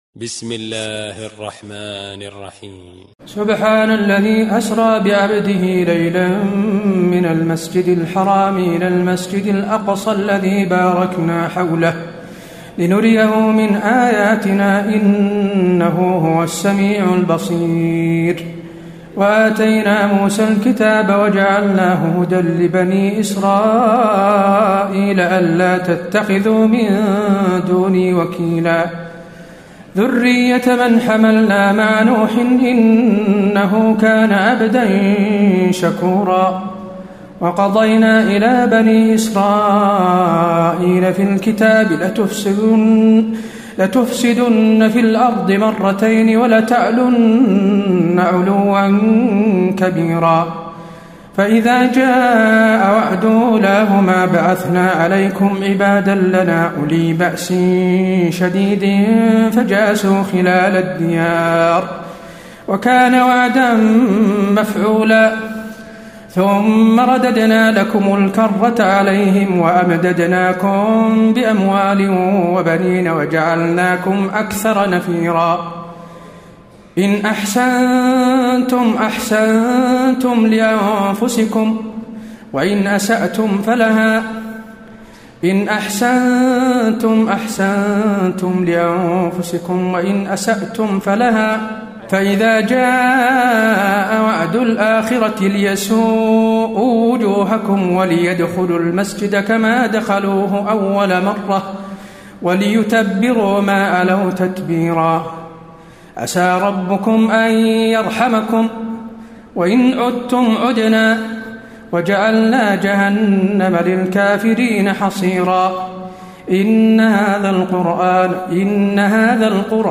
تراويح الليلة الرابعة عشر رمضان 1434هـ سورة الإسراء Taraweeh 14 st night Ramadan 1434H from Surah Al-Israa > تراويح الحرم النبوي عام 1434 🕌 > التراويح - تلاوات الحرمين